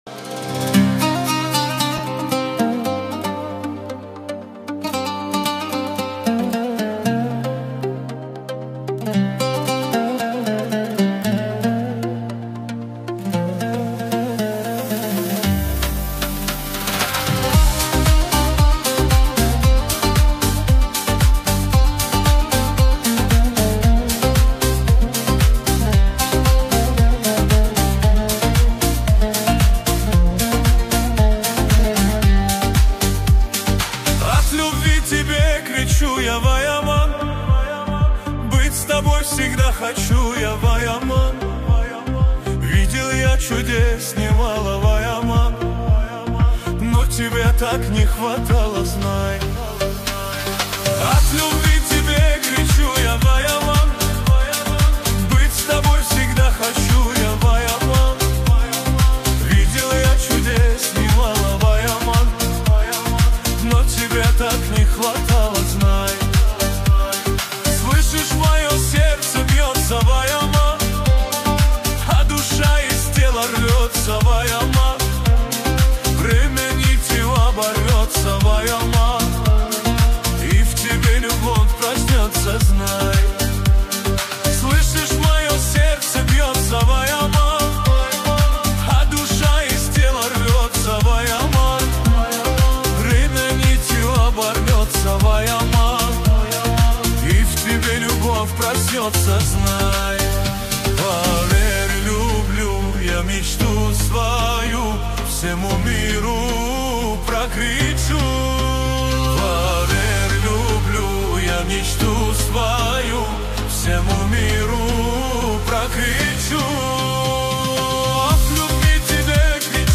Качество: 320 kbps, stereo
Ремиксы, 2026